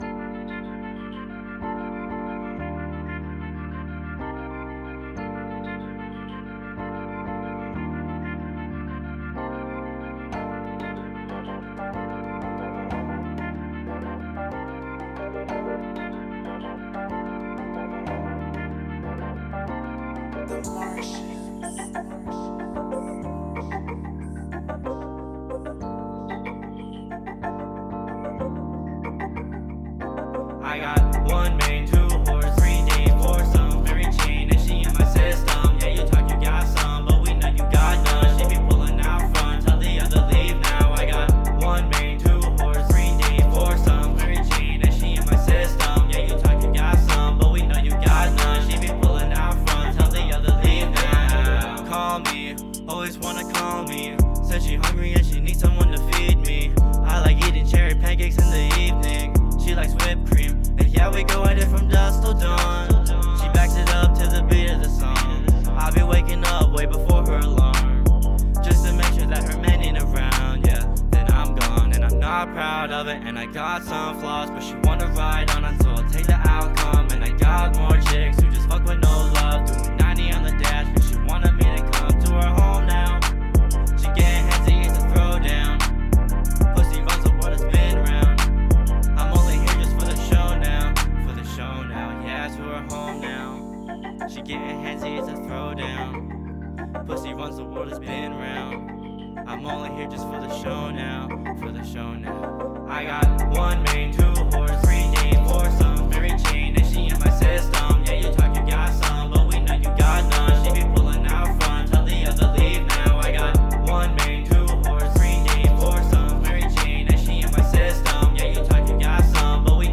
To me, I feel like this mix sucks.
I've tried everything from playing with levels, compressors, reverb and saturation and I can never find the sound I like. Do you guys have any suggestions to make this mix sound smoother?